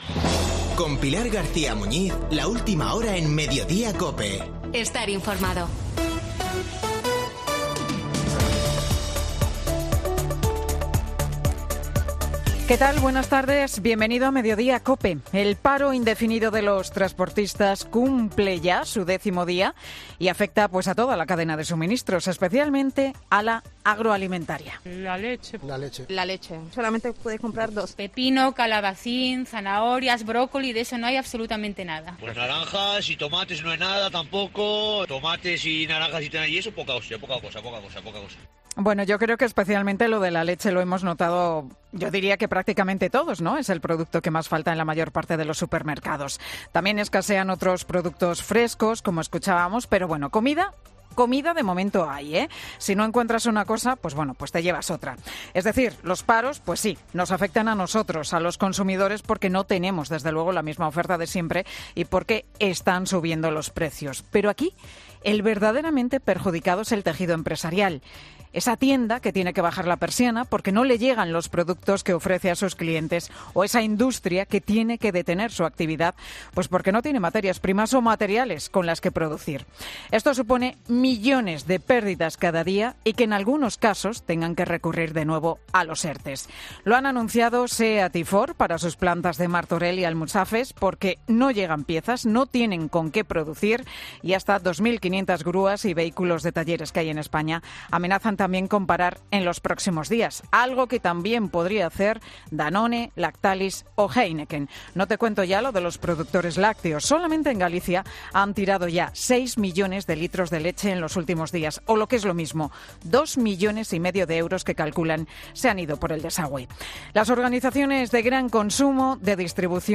AUDIO: El monólogo de Pilar García Muñiz, en Mediodía COPE